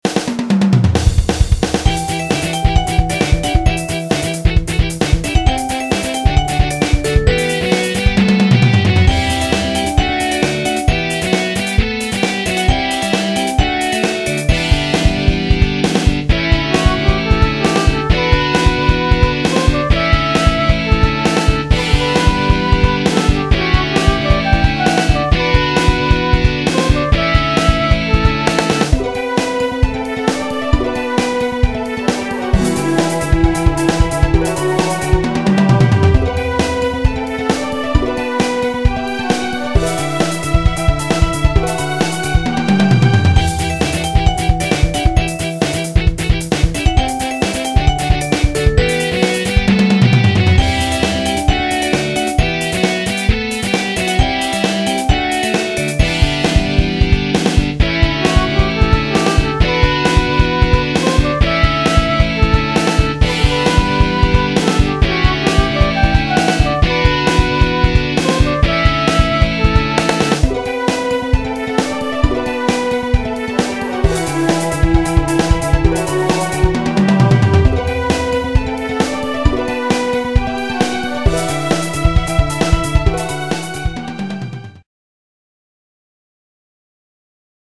orchestral